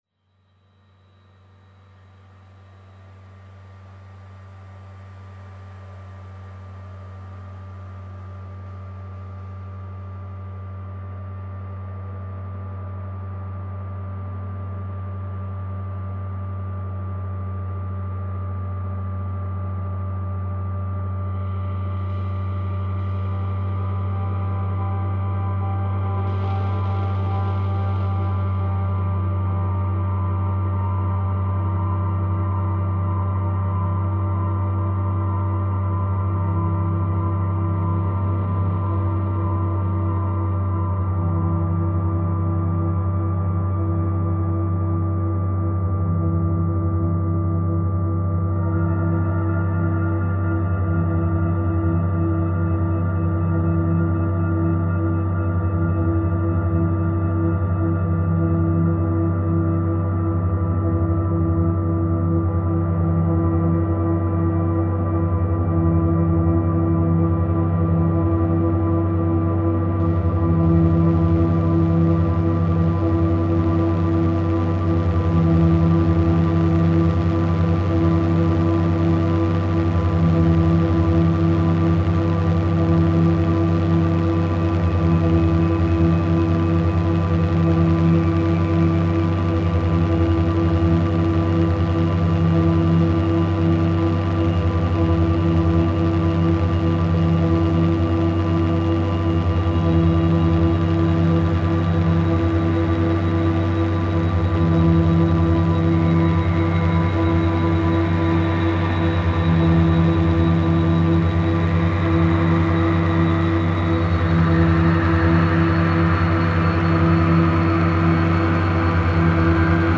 File under: Experimental / Industrial / Noise